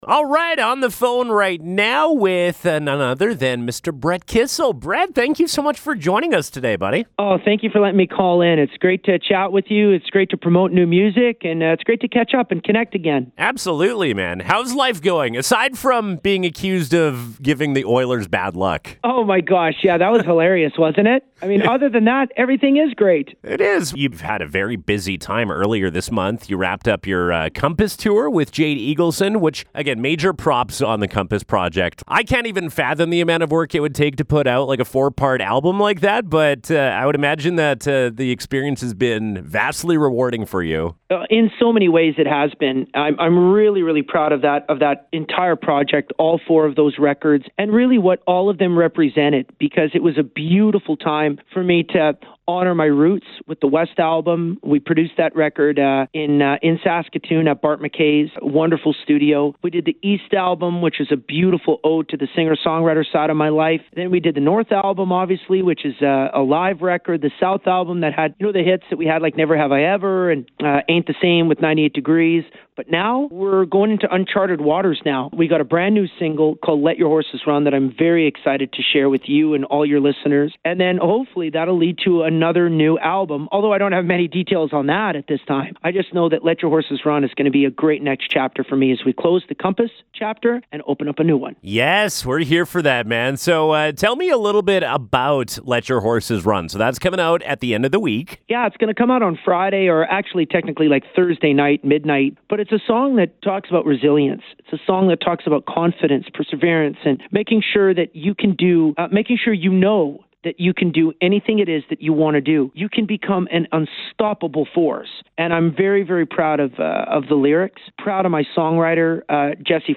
Interview: Brett Kissel